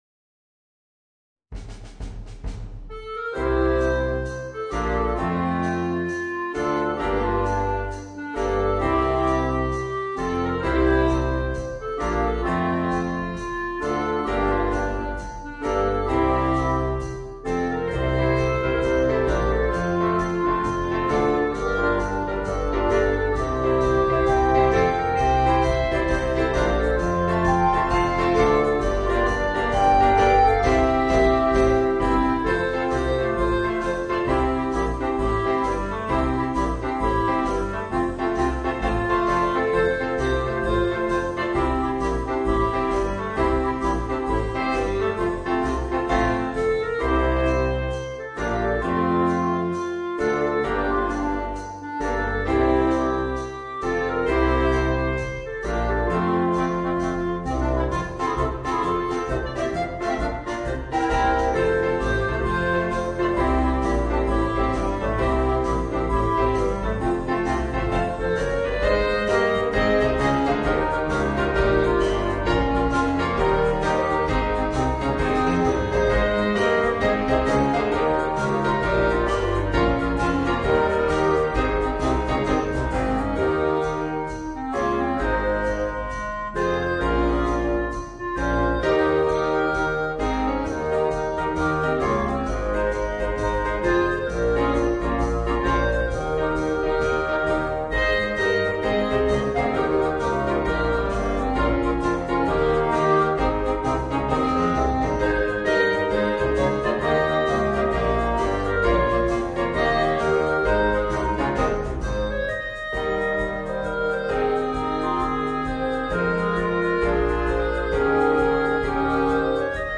Voicing: Woodwind Quartet and Rhythm Section